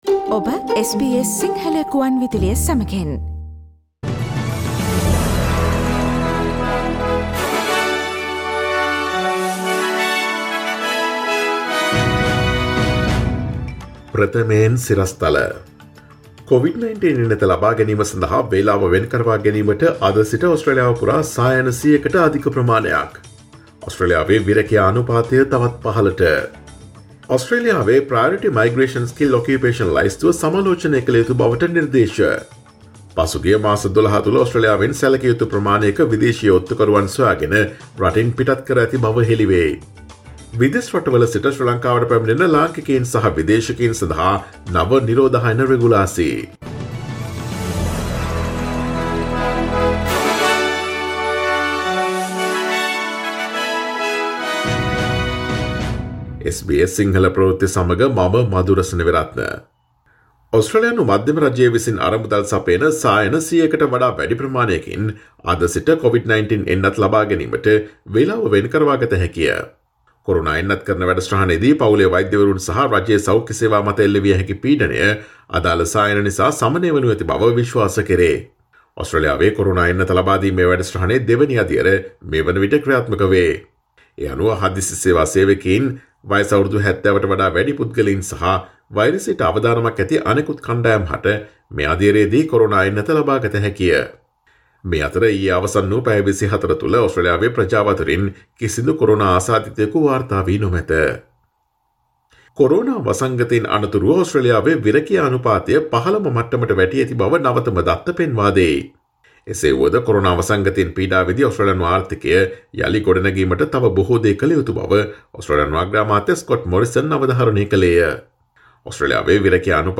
Here are the most prominent Australian and Sri Lankan news highlights from SBS Sinhala radio daily news bulletin on Friday 19 March 2021.